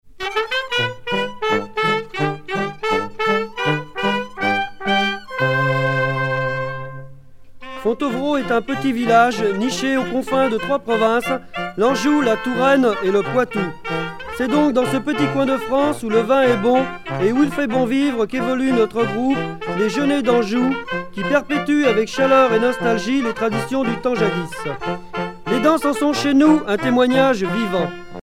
danse : polka
groupe folklorique
Pièce musicale éditée